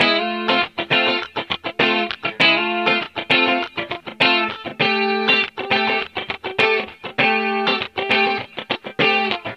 Loops guitares rythmique- 100bpm 2
Guitare rythmique 29